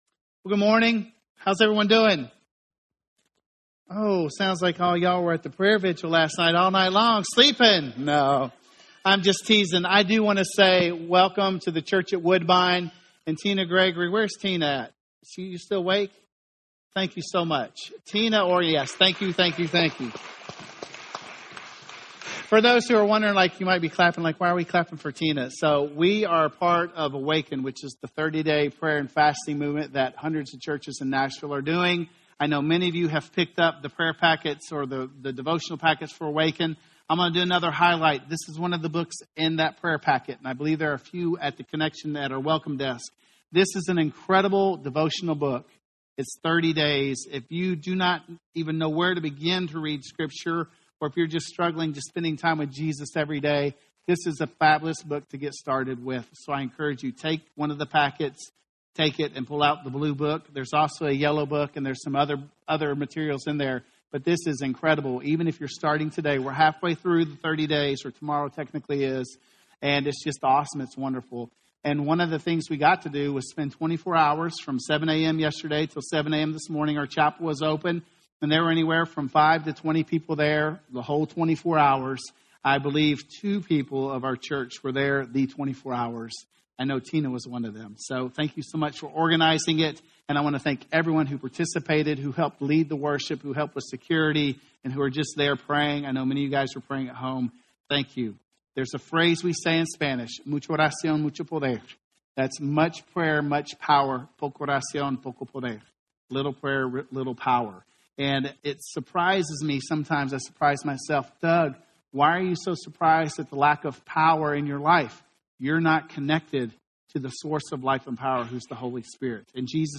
The Story of Separation - Sermon - Woodbine